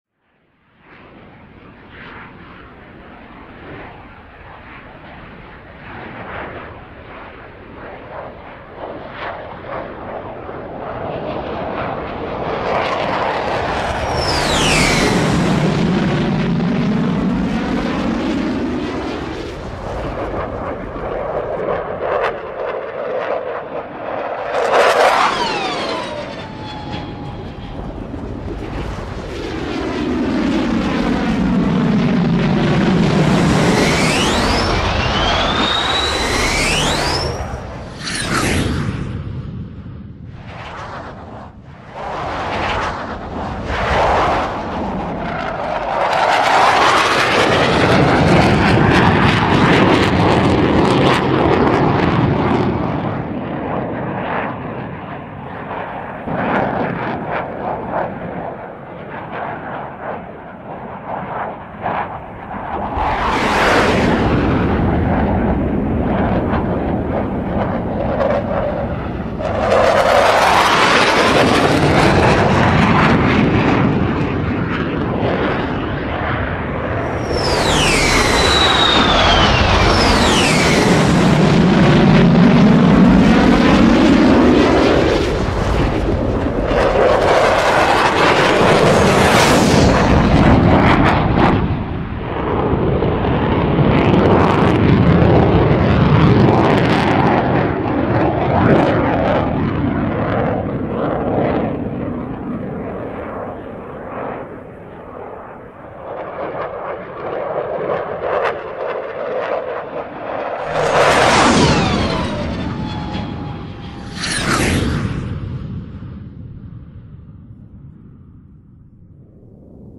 دانلود صدای رد شدن جت 1 از ساعد نیوز با لینک مستقیم و کیفیت بالا
جلوه های صوتی
برچسب: دانلود آهنگ های افکت صوتی حمل و نقل دانلود آلبوم صدای رد شدن جت از افکت صوتی حمل و نقل